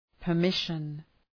Προφορά
{pər’mıʃən} (Ουσιαστικό) ● άδεια